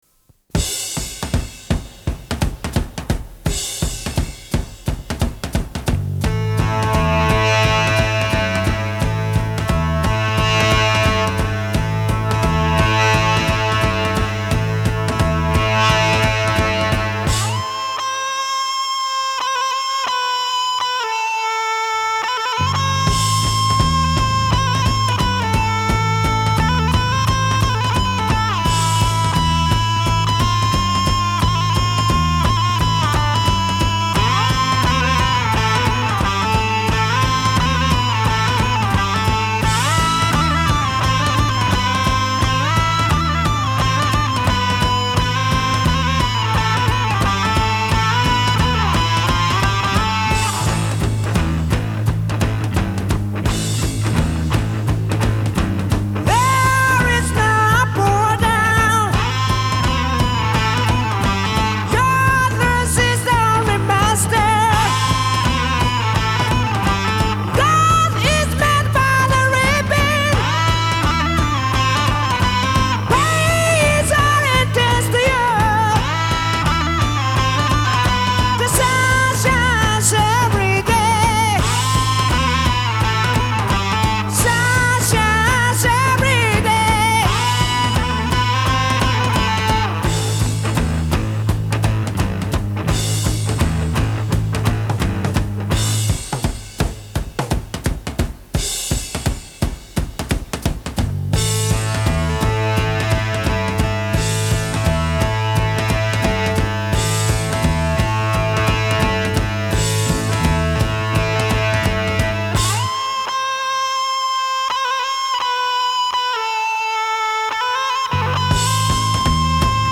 곡 전체를 휘감는 기타리프와 지속적인 긴장감은